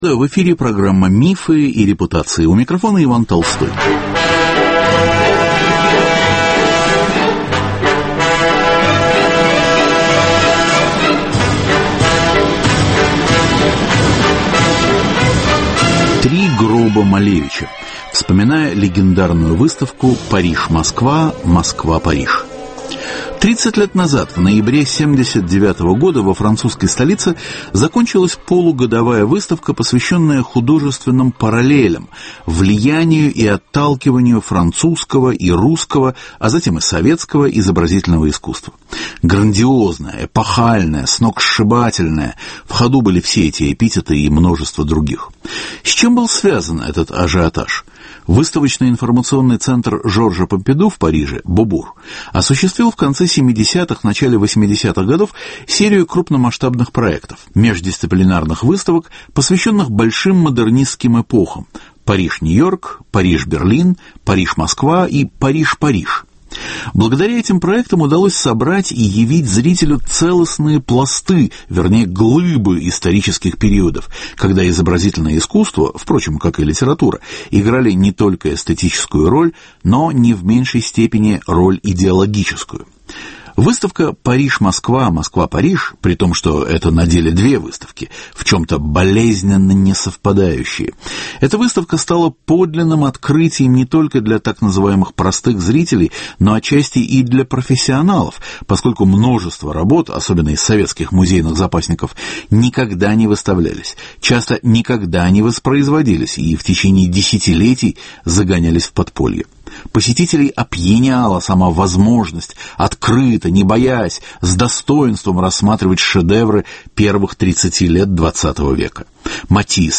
В нашей программе о выставке рассказывают кураторы, художники, журналисты.